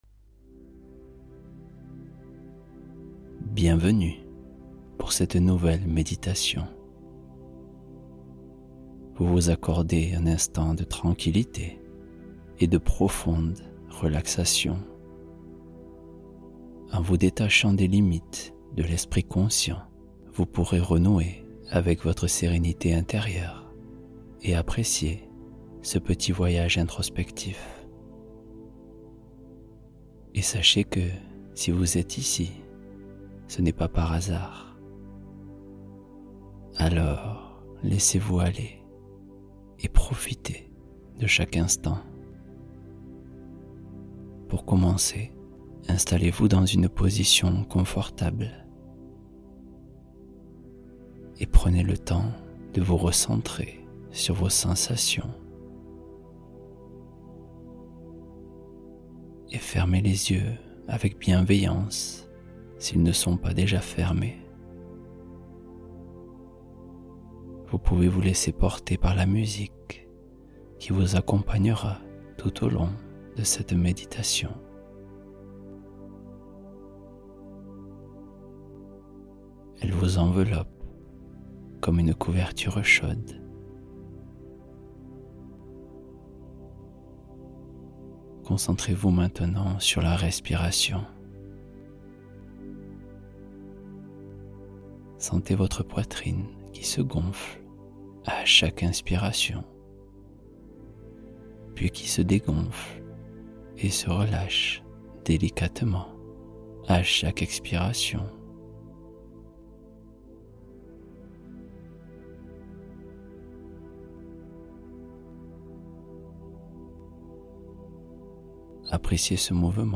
Soir narratif apaisant — Histoire guidée pour l’endormissement